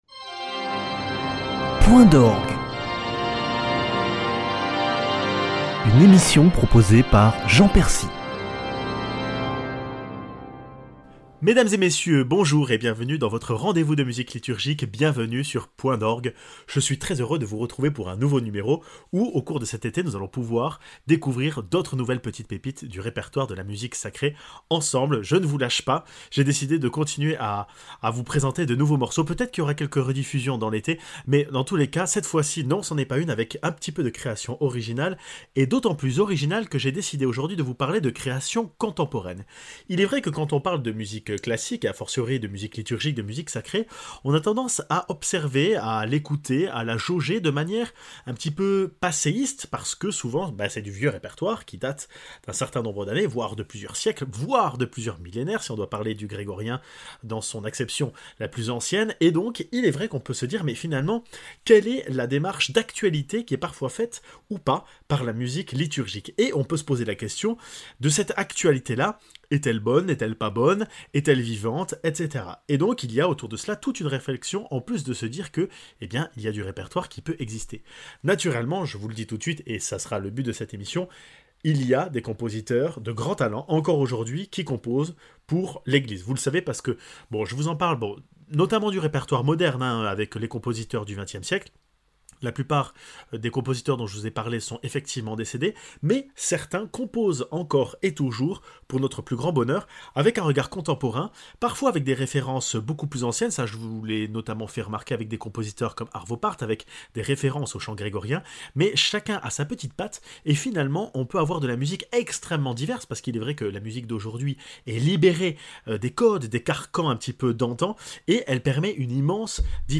J’apprécie d’entendre de l’orgue au cours de l’émission, un instrument hors du commun, mais trop souvent mis de coté.